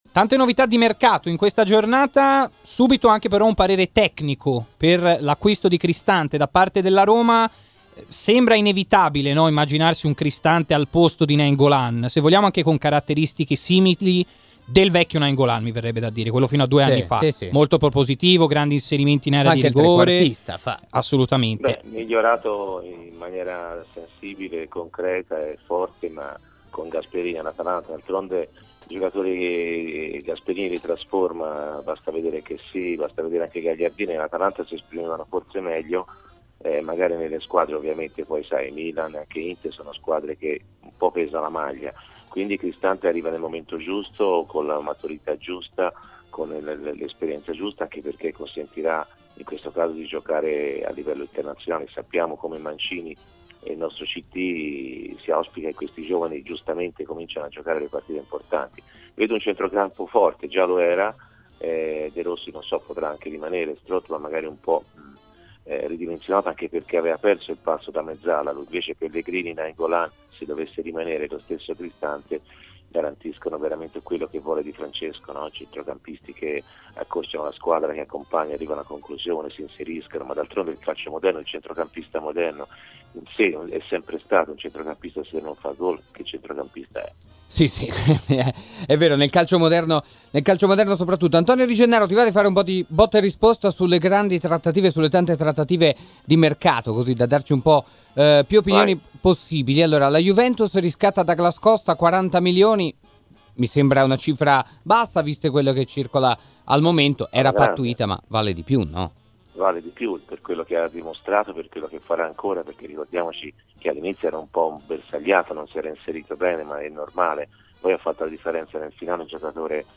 L'opinionista ed ex giocatore Antonio Di Gennaro, in diretta nel Live Show di RMC Sport, ha parlato di Cristante, della situazione del Milan e di altre trattative di mercato: